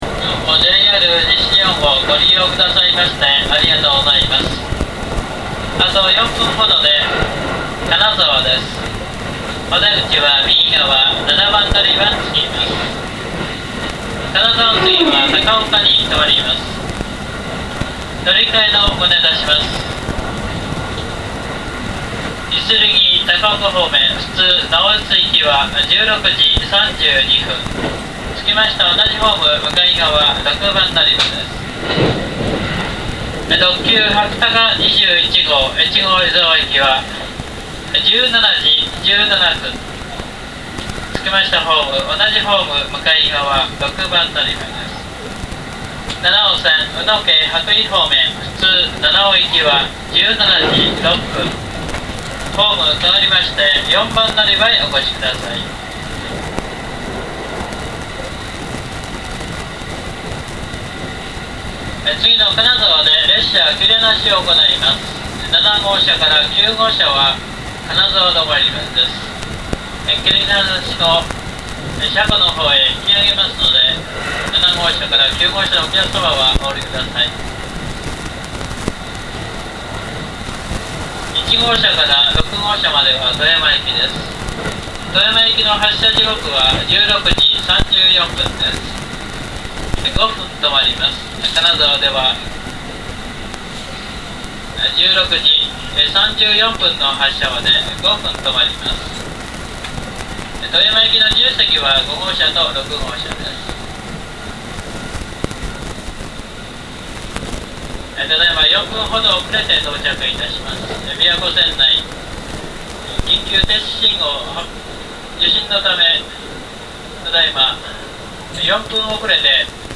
◆車内放送◆
• 「走行音がうるさいぞ」と思われる方もいらっしゃるかもしれませんが、あえて走行音を絡めることで、
金沢駅到着前放送           681系
普通車指定席
• チャイムはたぶん、そもそも鳴っていない。